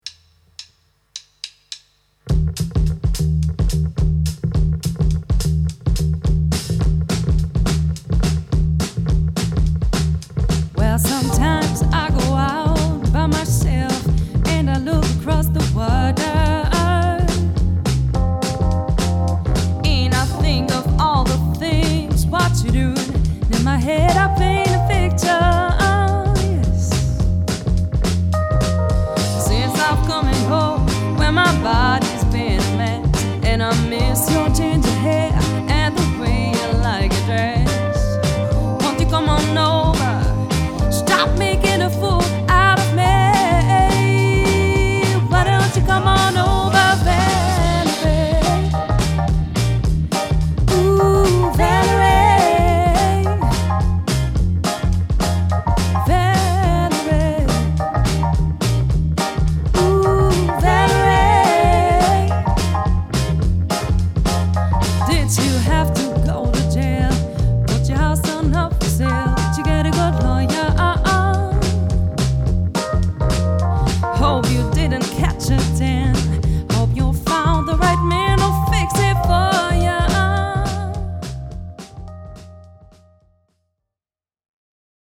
(Tanz / Band)